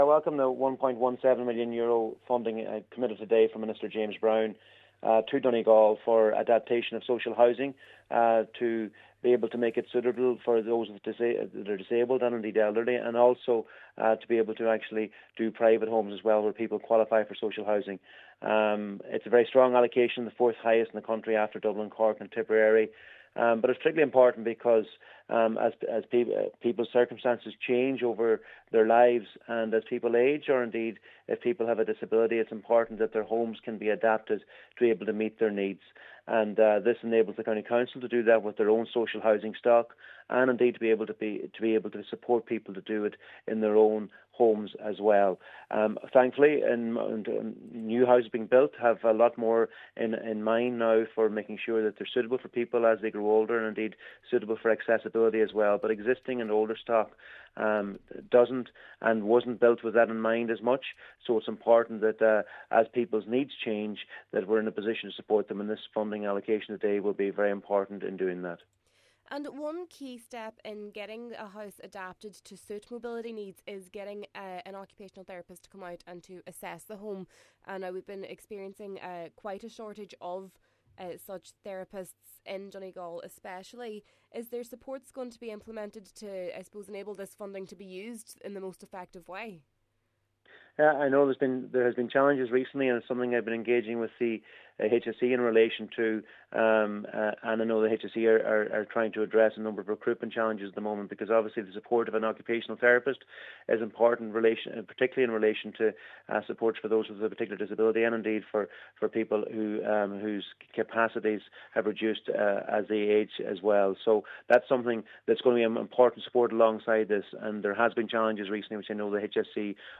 Minister McConalogue says he has been engaging with the HSE in relation to the matter: